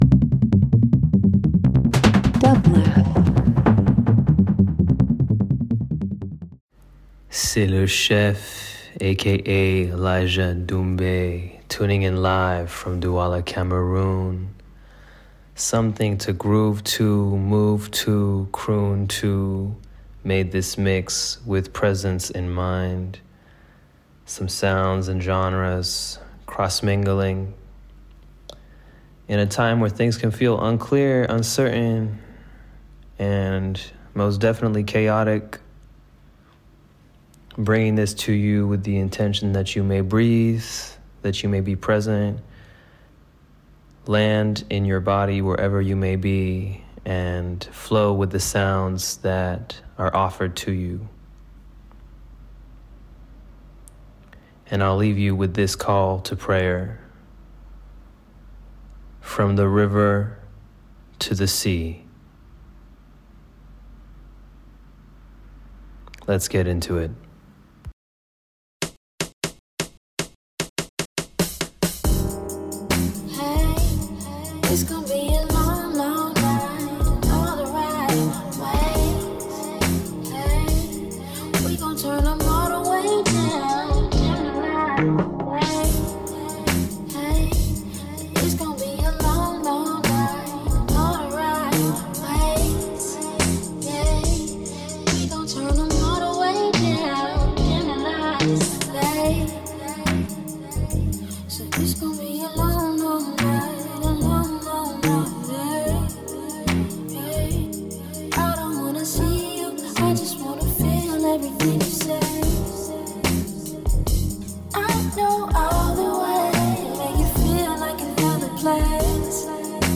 Afrobeats House R&B